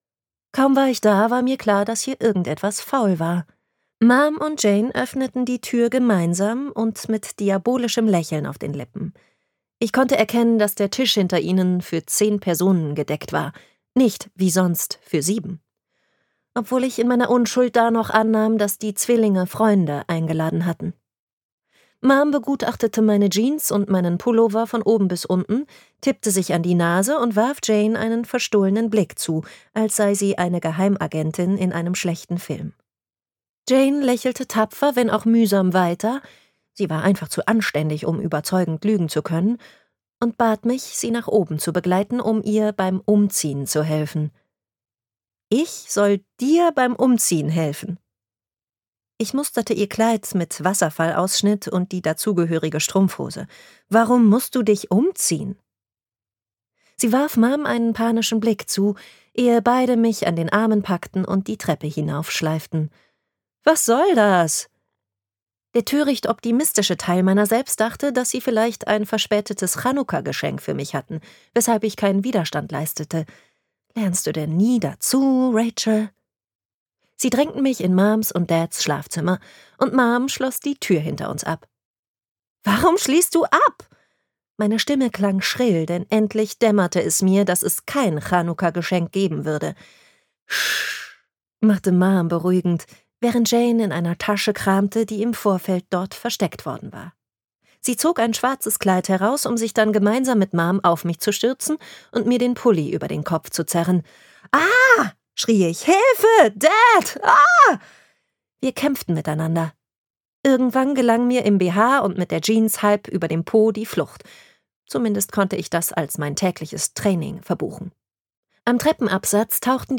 Gekürzt Autorisierte, d.h. von Autor:innen und / oder Verlagen freigegebene, bearbeitete Fassung.
Hörbuchcover von Gruppenchat zum Frühstück